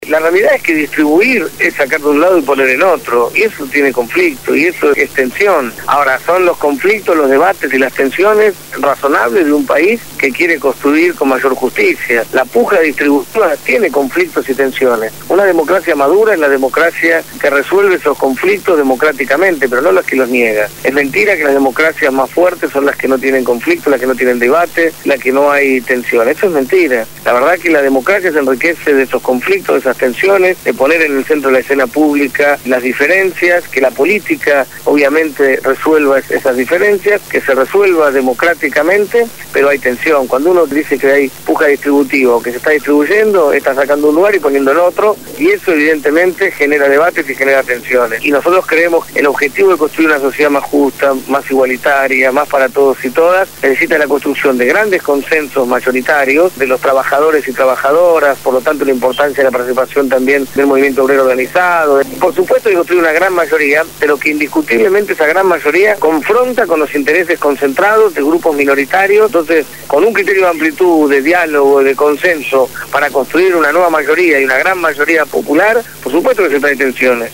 Lo dijo Martín Sabbatella, candidato a gobernador bonaerense por Nuevo Encuentro (cuya boleta llevará la fórmula presidencial Cristina Fernandez-Amado Boudou) en «Desde el Barrio».